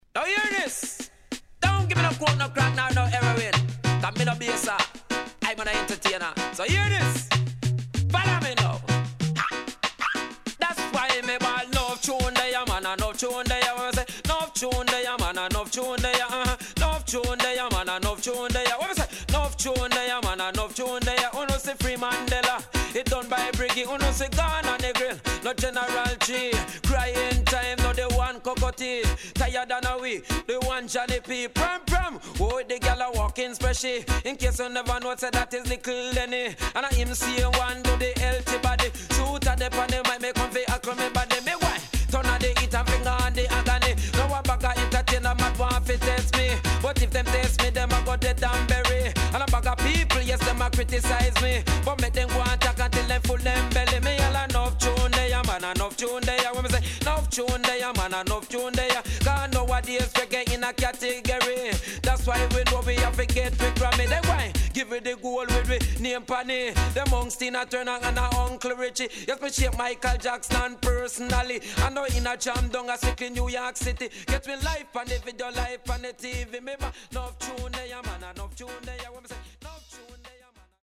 HOME > Back Order [DANCEHALL LP]